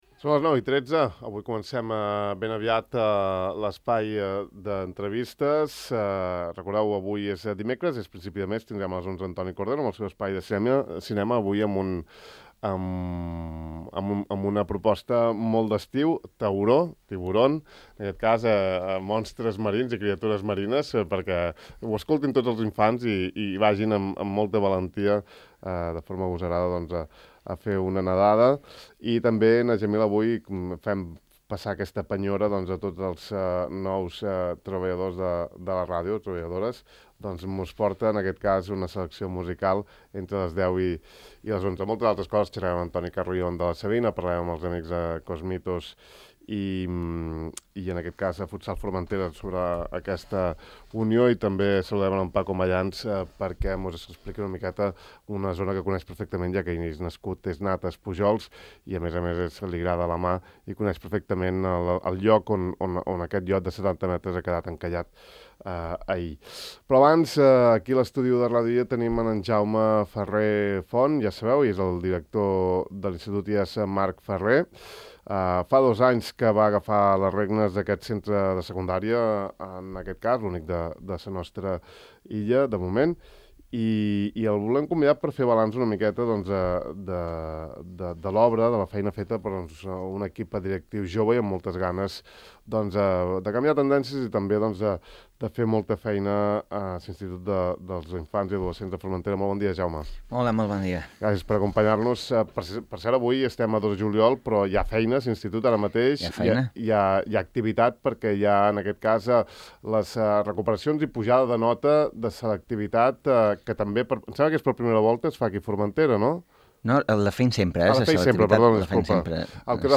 N'hem volgut fer balanç amb ell en entrevista aquest matí al De far a far, que ha començat per les notícies més recents, en aquest cas la selectivitat i cert desànim i abatiment entre un